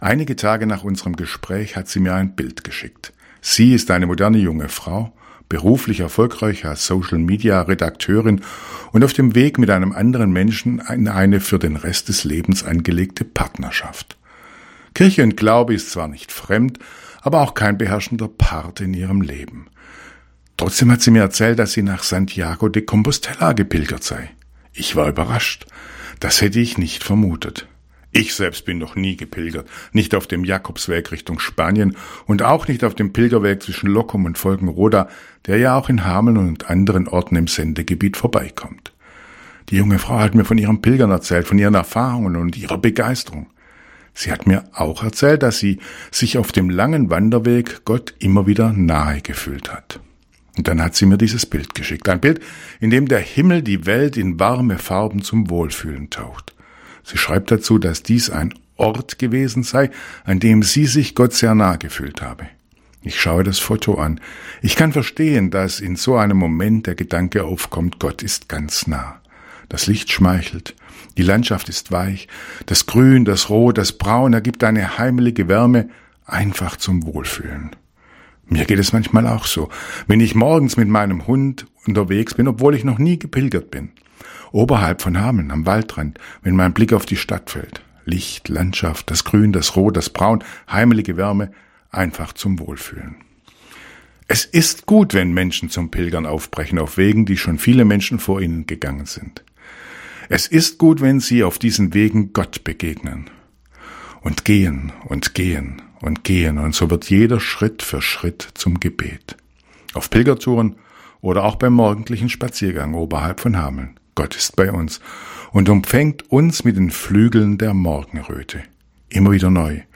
Radioandacht vom 28. Juli